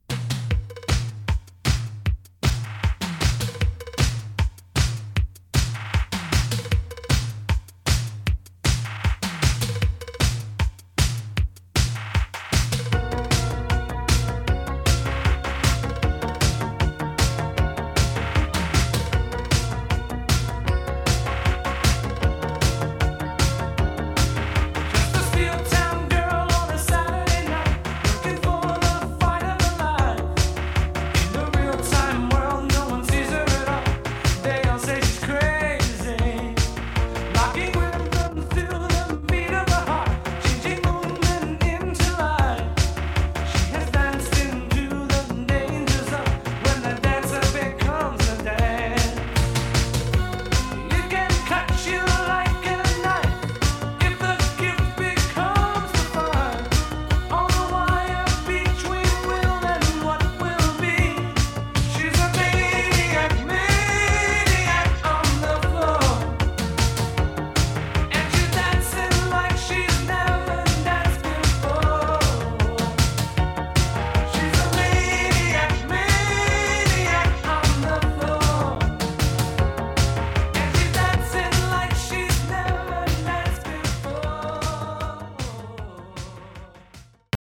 Below is a record made from the PMD-350 thats been played back on it: